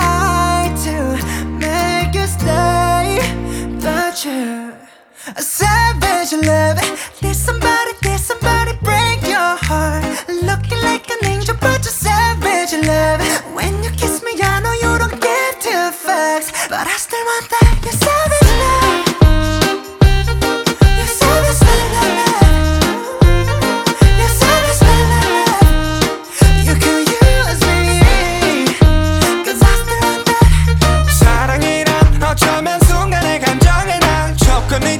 Off-beat гитары и расслабленный ритм
Жанр: Поп музыка / Регги